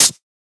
Closed Hats
edm-hihat-23.wav